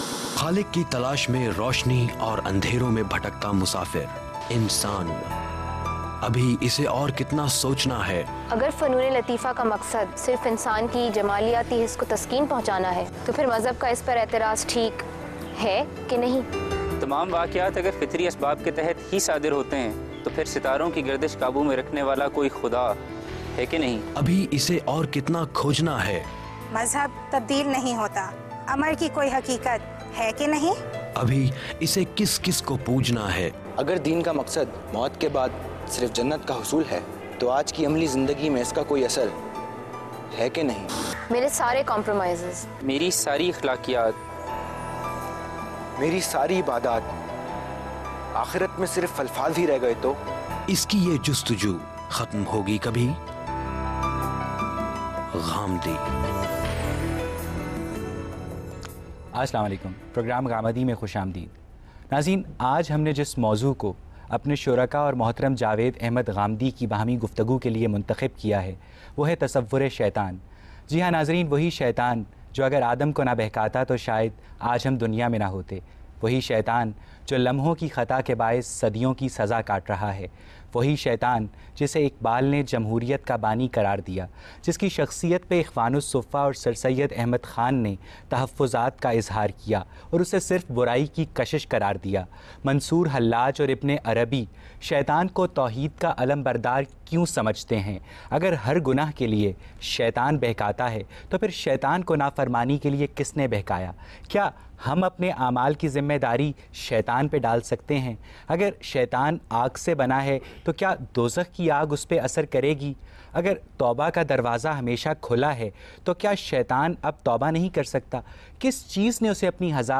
Questions and Answers on the topic “The concept of Satan” by today’s youth and satisfying answers by Javed Ahmad Ghamidi.